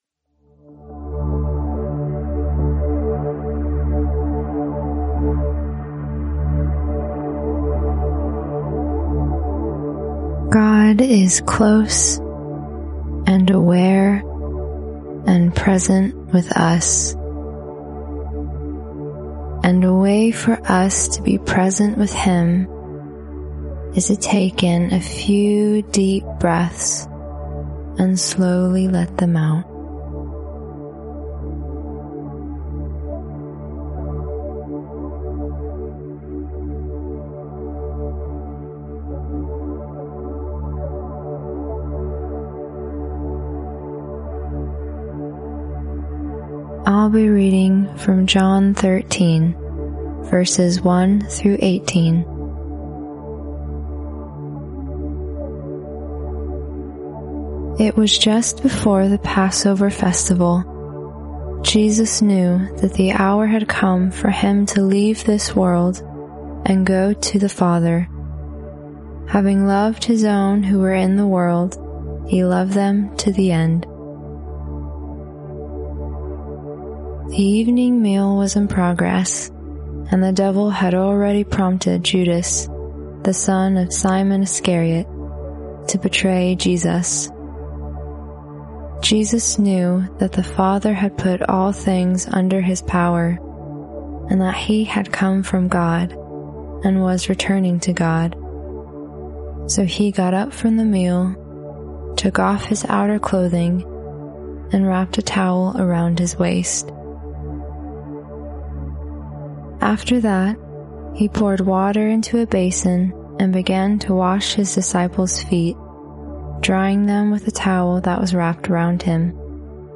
Click the audio to experience guided prayer through today's passage — then, after you're finished, take a moment with the daily breath prayer.